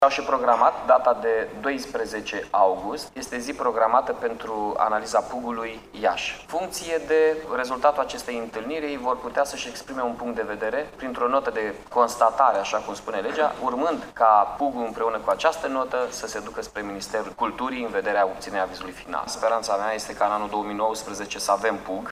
Prelungirea termenului de valabilitate a actualului Plan Urbanistic General al Iaşului, a fost votată, astăzi, în şedinţa Consiliului Local.